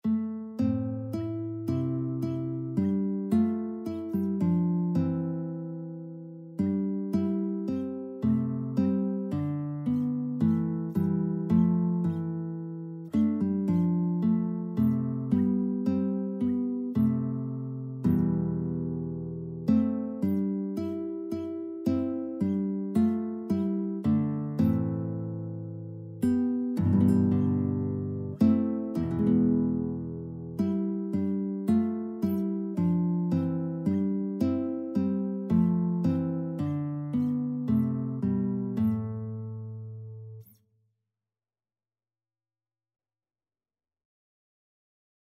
Christmas Christmas Guitar Sheet Music O Come, O Come, Emmanuel
A minor (Sounding Pitch) (View more A minor Music for Guitar )
Gently Flowing = c. 110
4/4 (View more 4/4 Music)
Instrument:
Guitar  (View more Intermediate Guitar Music)
Traditional (View more Traditional Guitar Music)